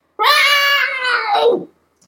cat.ogg.mp3